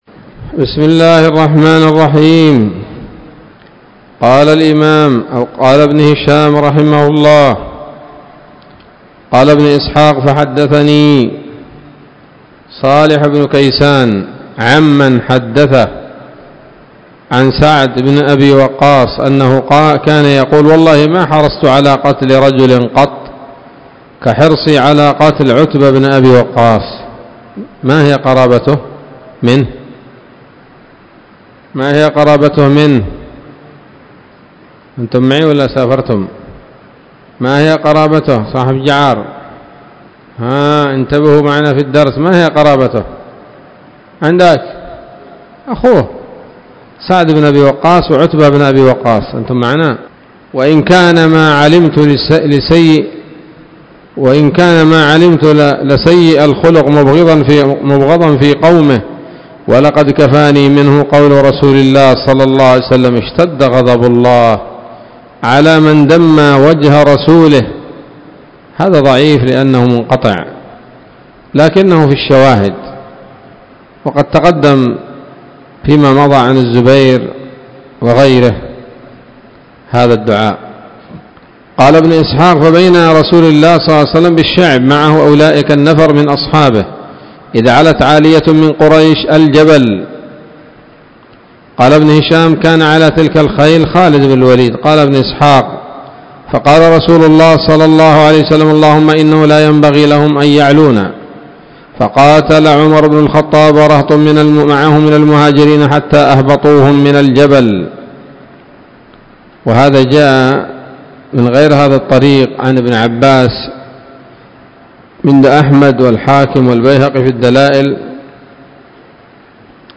الدرس الحادي والستون بعد المائة من التعليق على كتاب السيرة النبوية لابن هشام